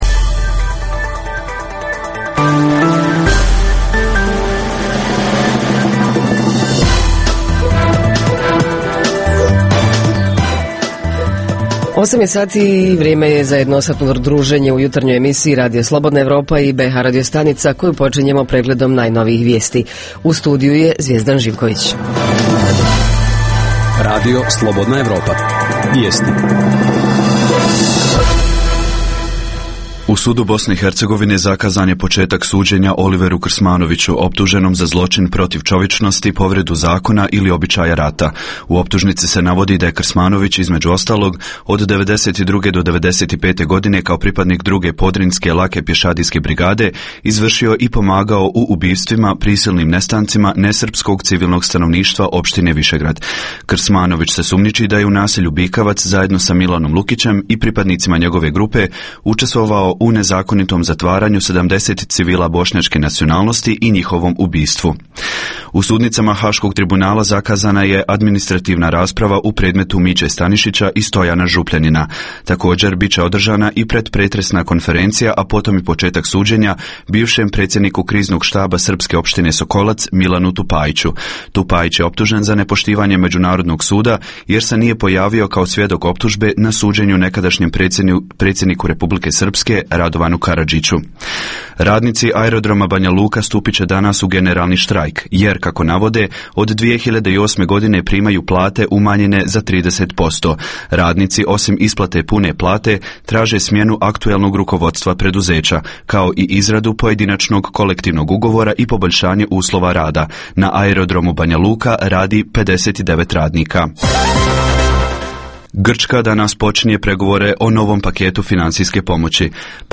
Nevladine organizacije koje se bave promovisanjem svojeg grada - na koji način to rade, koji su projekti u pitanju, koje oblasti, šta su ciljevi? Reporteri iz cijele BiH javljaju o najaktuelnijim događajima u njihovim sredinama.
Redovni sadržaji jutarnjeg programa za BiH su i vijesti i muzika.